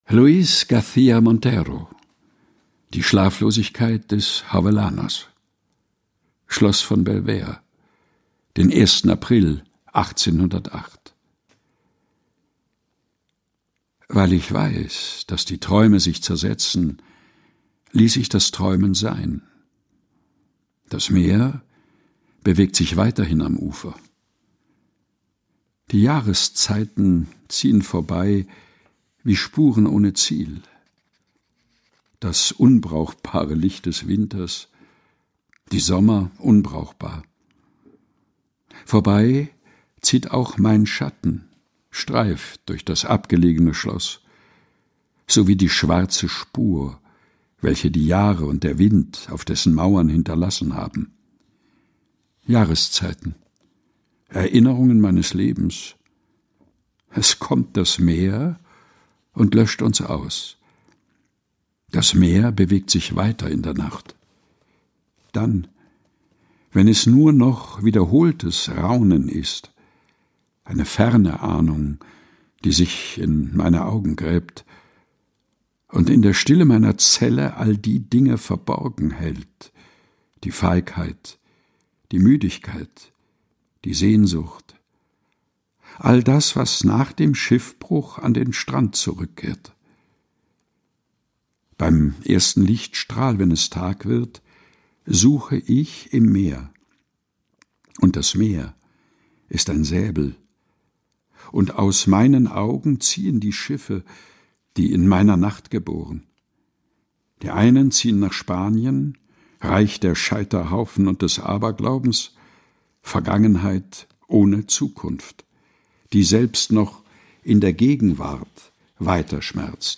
Ohrenweide ist der tägliche Podcast mit Geschichten, Gebeten und Gedichten zum Mutmachen und Nachdenken - ausgesucht und im heimischen Studio vorgelesen